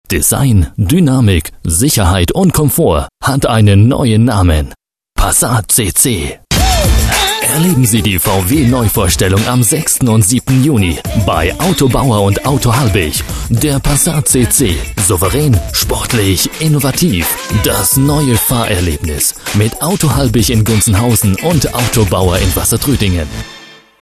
Sprecher deutsch für Radiowerbung, Tv-Werbung, Pc-Spiele, Industriefilme...
Sprechprobe: eLearning (Muttersprache):
german voice over talent